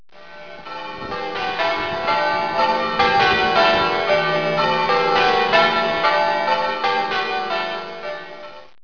A short recording of the bells (about 30 seconds download time)
bells.wav